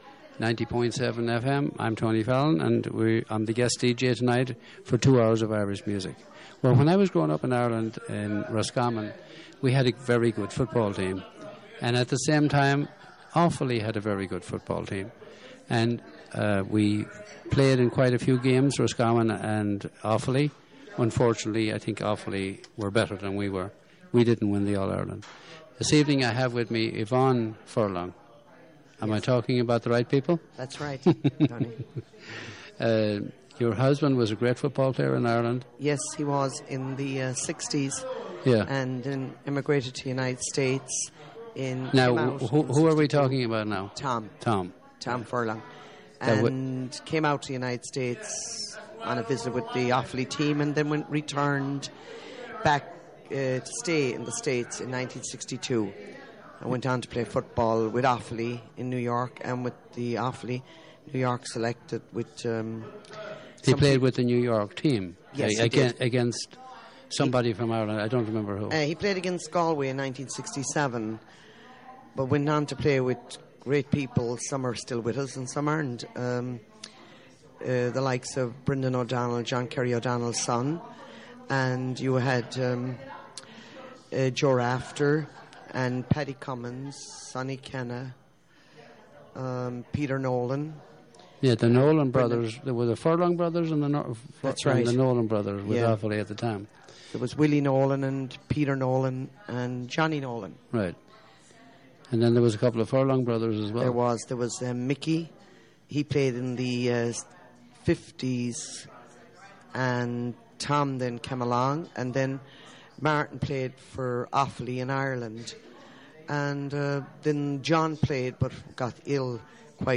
Interview
Live from Blackthorne Resort in East Durham.